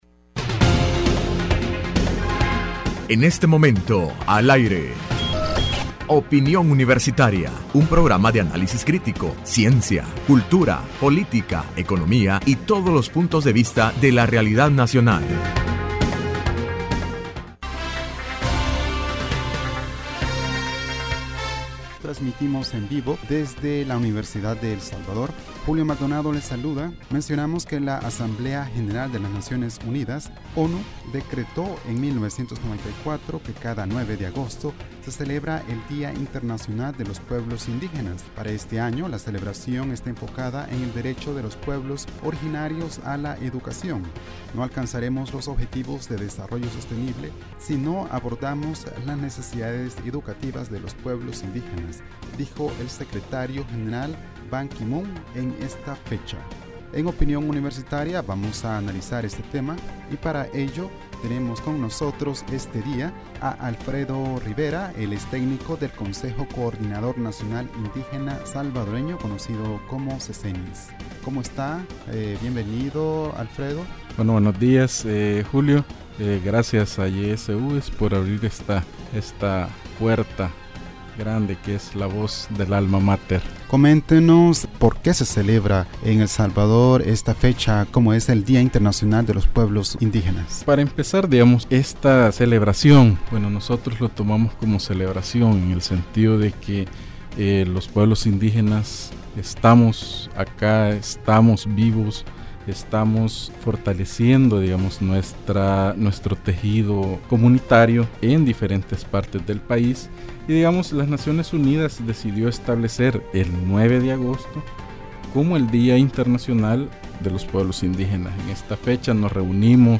Entrevista Opinión Universitaria (18 de Agosto) : Conmemoración del Día Internacional de los Pueblos Indígenas.